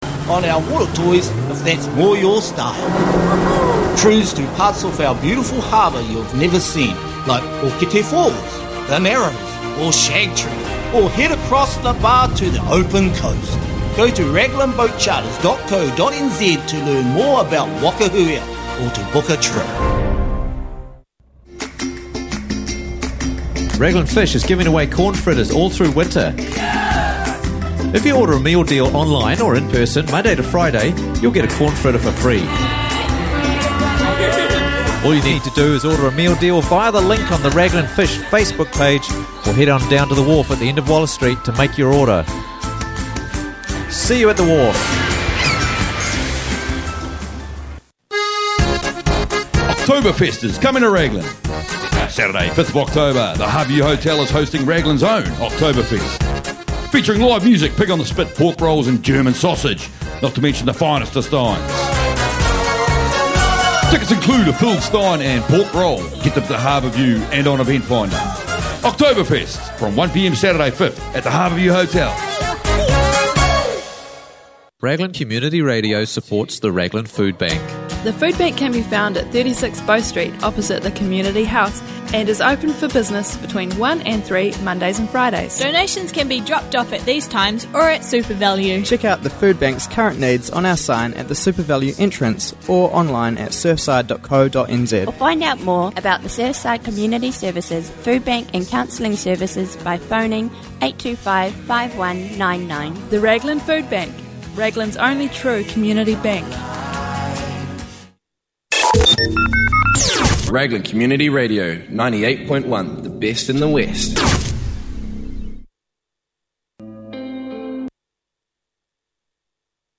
Morning Show interviews